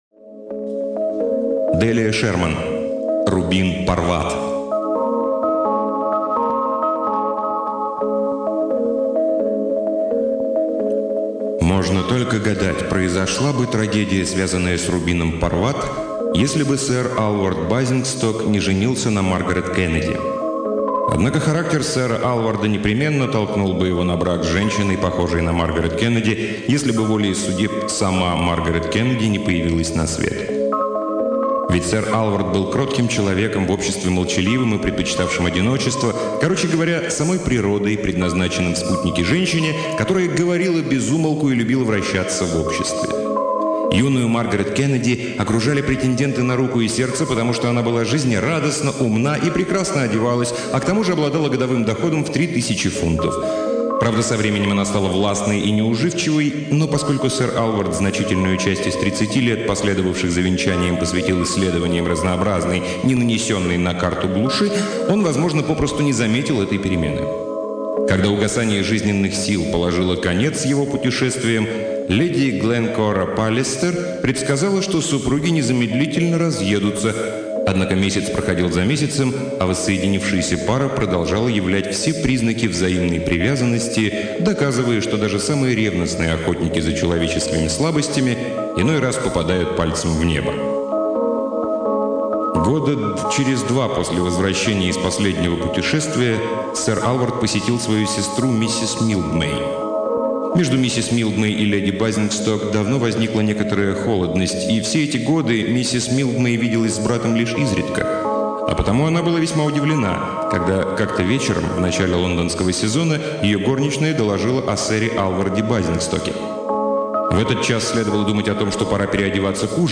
Аудиокнига Делия Шерман — Рубин Парват